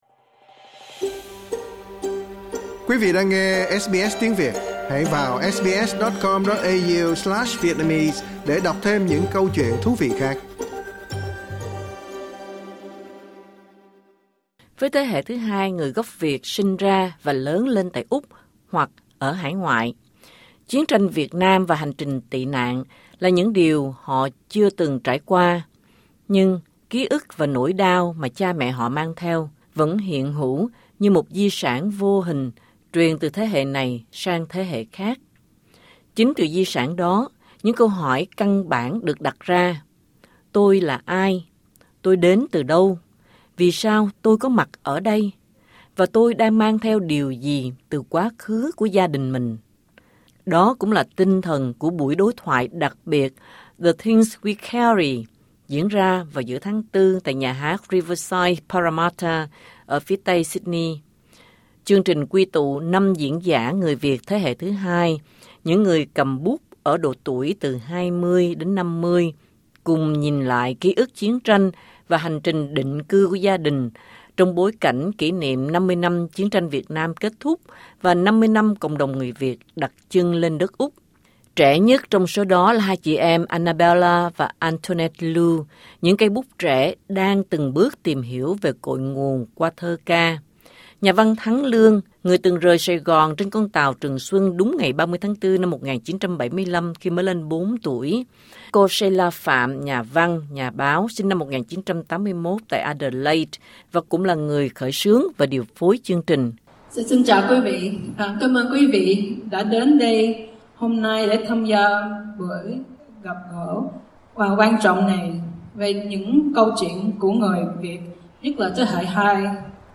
Buổi đối thoại đặc biệt “The Things We Carry” diễn ra vào giữa tháng Tư tại nhà hát Riverside Parramatta, phía Tây Sydney quy tụ năm diễn giả người Việt thế hệ thứ hai — những người cầm bút ở các độ tuổi từ 20 đến 50 — cùng nhìn lại ký ức chiến tranh và hành trình định cư của gia đình họ, trong bối cảnh kỷ niệm 50 năm chiến tranh Việt Nam kết thúc và 50 năm cộng đồng người Việt đặt chân lên đất Úc.